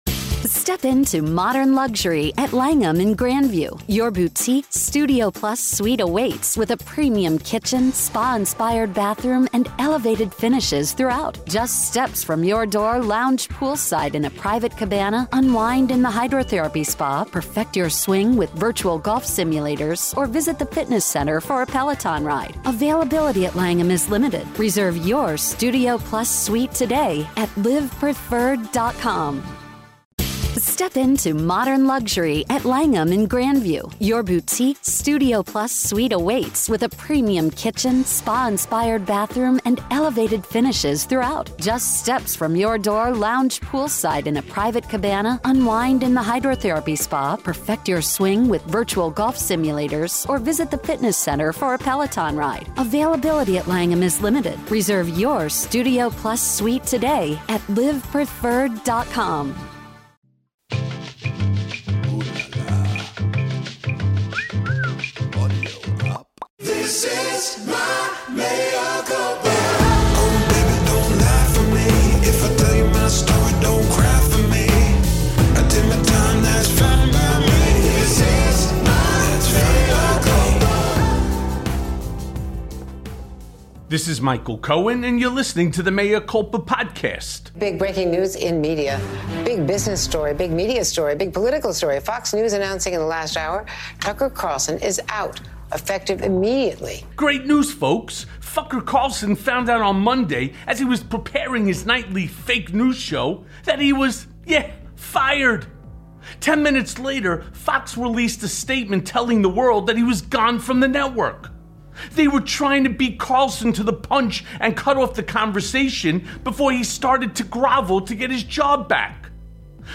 E. Jean Carroll Rape Charge Could Prove Deadly to Trump Campaign + A Conversation with Bryan Tyler Cohen
Mea Culpa welcomes back fellow podcaster and political junkie, Brian Tyler Cohen.